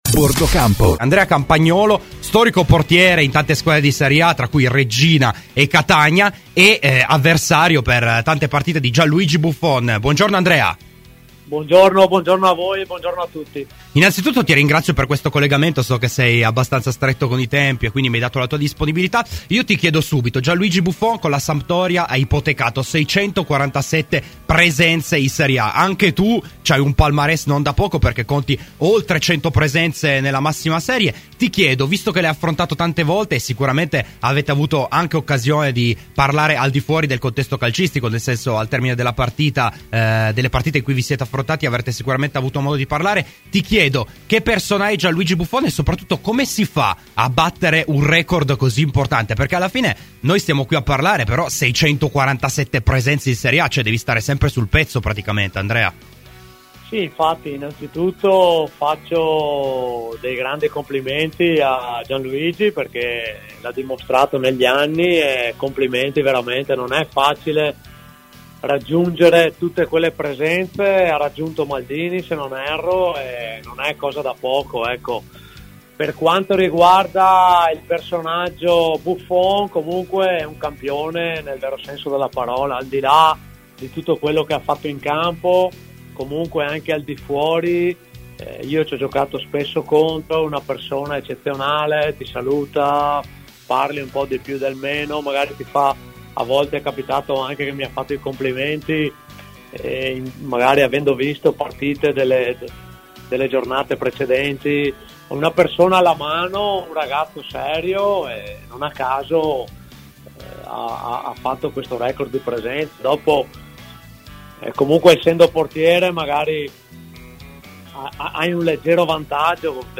ai microfoni di "Bordocampo"